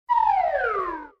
B_CHUTE.mp3